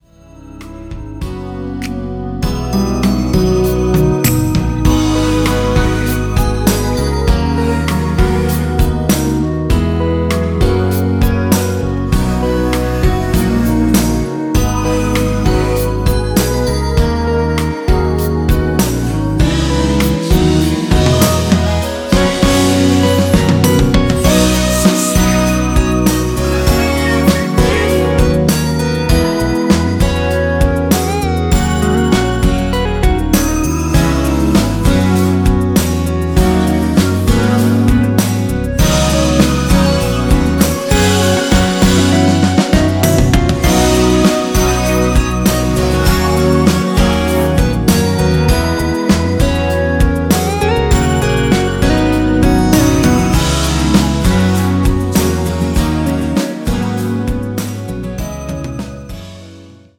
원키에서(-3)내린 멜로디와 코러스 포함된 MR입니다.(미리듣기 참조)
앞부분30초, 뒷부분30초씩 편집해서 올려 드리고 있습니다.
중간에 음이 끈어지고 다시 나오는 이유는